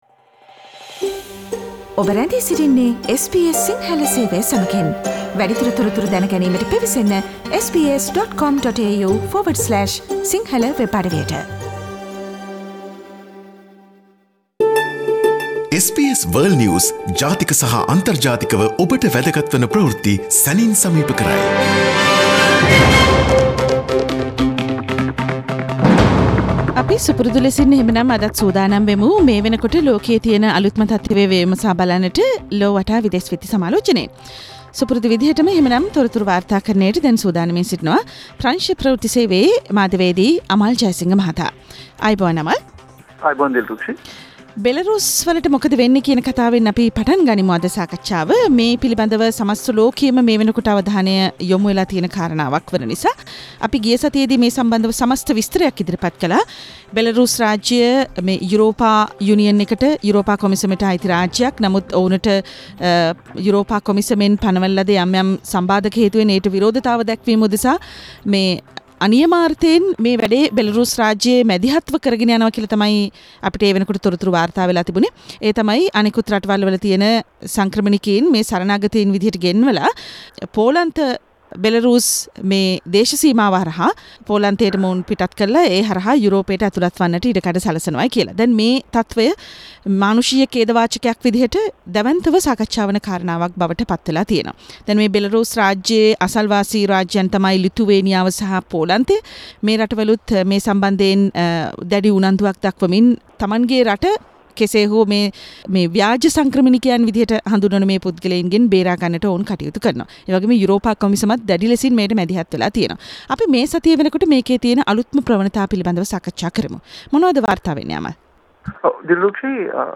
world's prominent news highlights in a few minutes- listen to SBS Si Sinhala Radio's weekly world News wrap on Friday